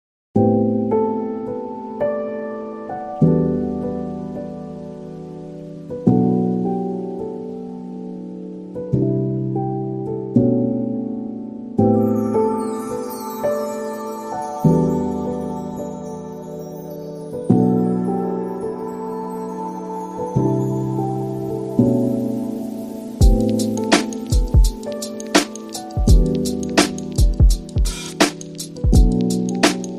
# chillhop # lofi # chill